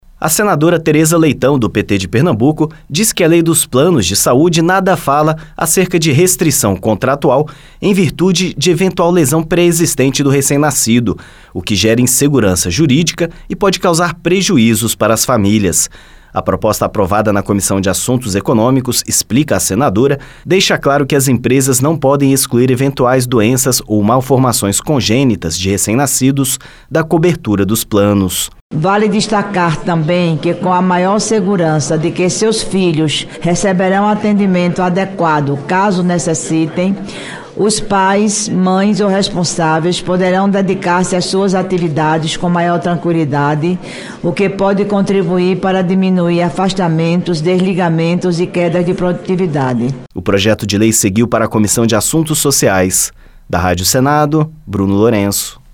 A relatora, senadora Teresa Leitão (PT-PE), diz que proposta deixa claro na lei esse direito.